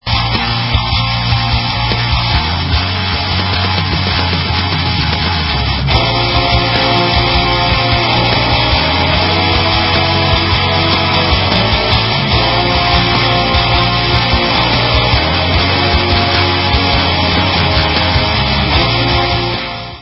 PROG. THRASH METAL